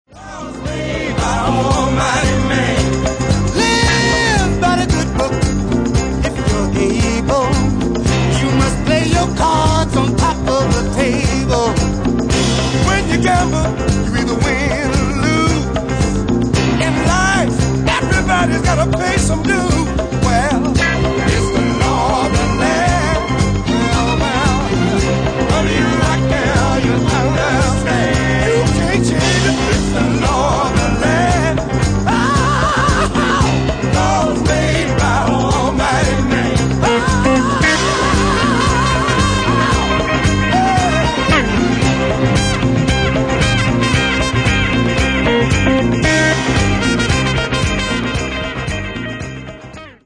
Genere:   Soul Funky